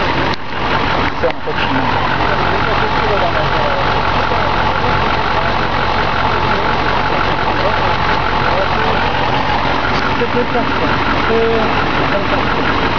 Oldtimer festival Slavkov 2006
vrčení naftového motoru vozu Oldsmobile Delta 88 Diesel (zvukový klip WAV o délce 12 s).